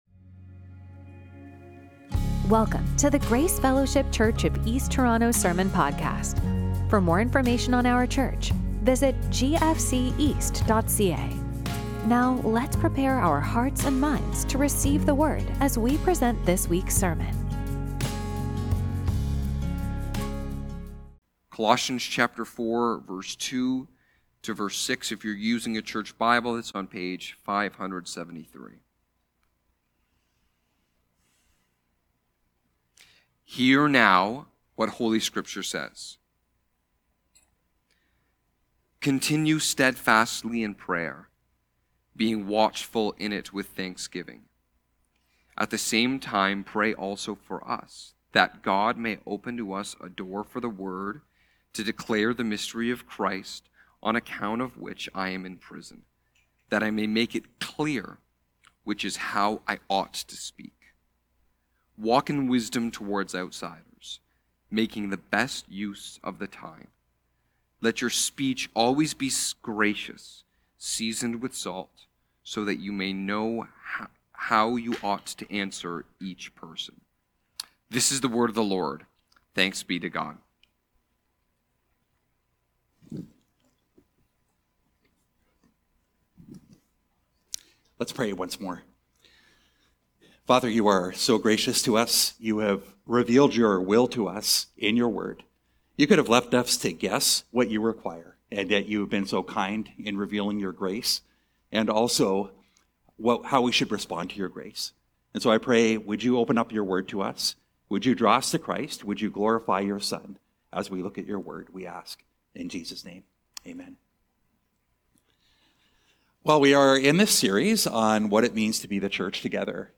Sermons from Grace Fellowship Church East Toronto